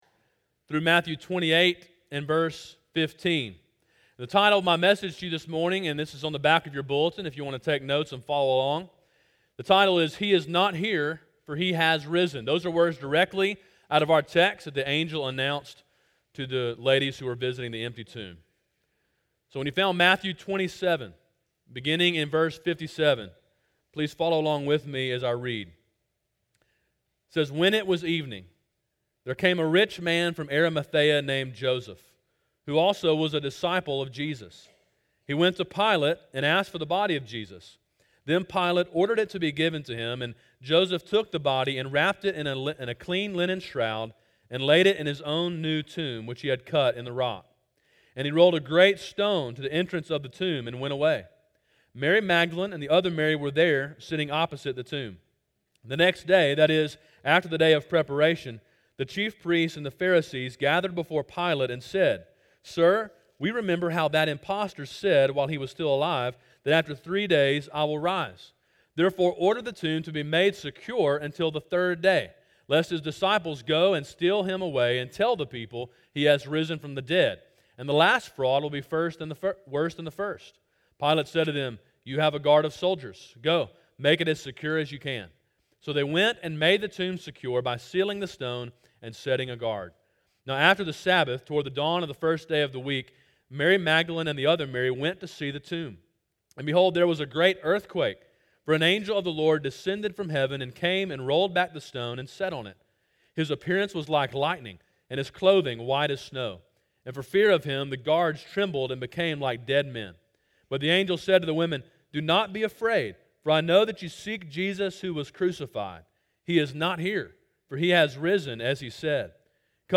A sermon preached on Easter Sunday, April 16, 2017.